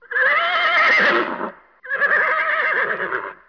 دانلود صدای حیوانات جنگلی 36 از ساعد نیوز با لینک مستقیم و کیفیت بالا
جلوه های صوتی